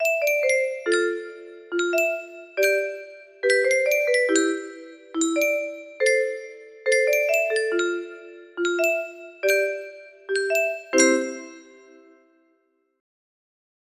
thr music box melody